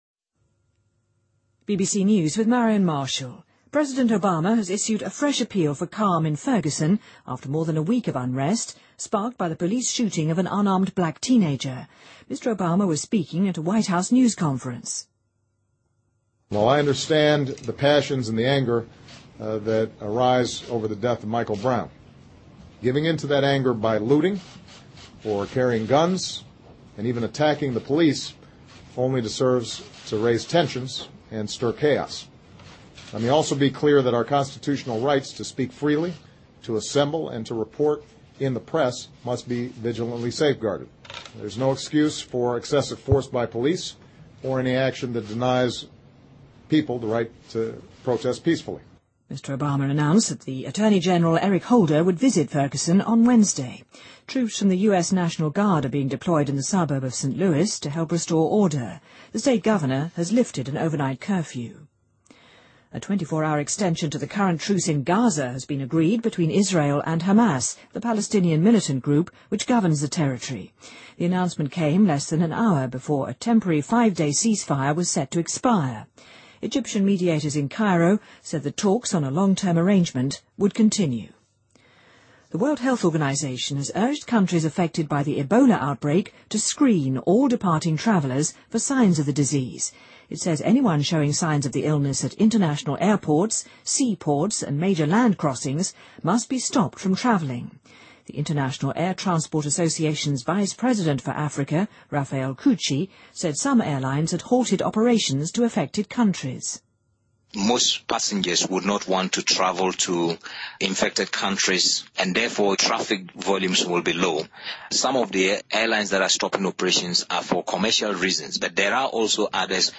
BBC news,奥巴马总统再次呼吁弗格森民众保持冷静